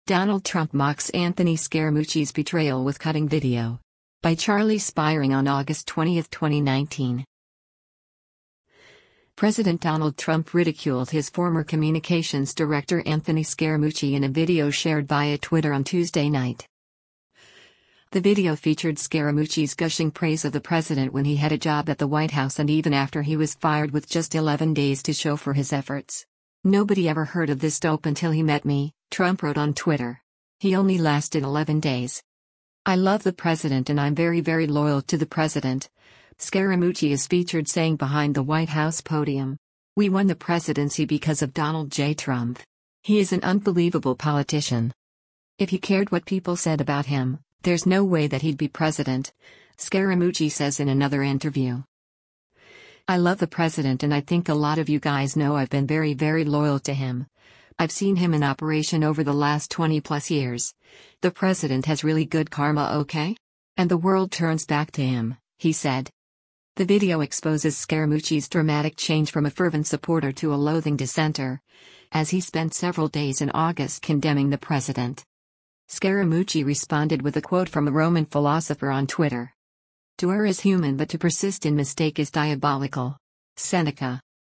“I love the president and I’m very very loyal to the president,” Scaramucci is featured saying behind the White House podium.
“If he cared what people said about him, there’s no way that he’d be president,” Scaramucci says in another interview.